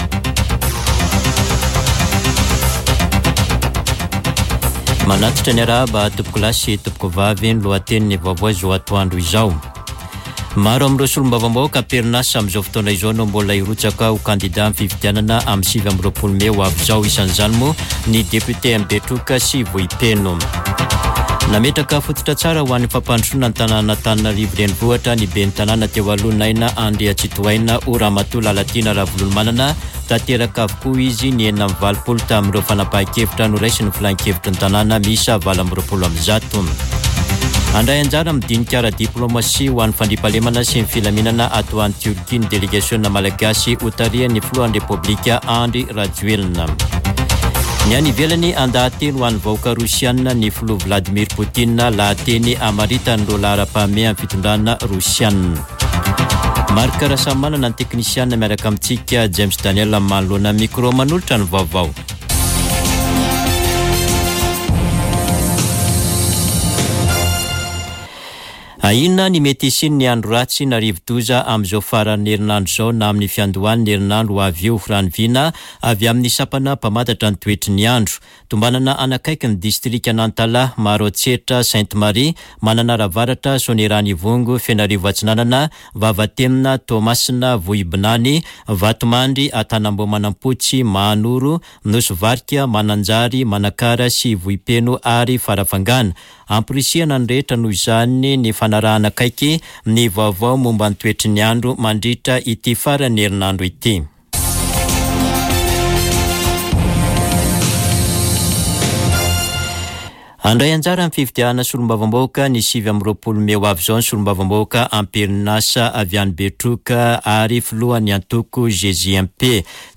[Vaovao antoandro] Alakamisy 29 febroary 2024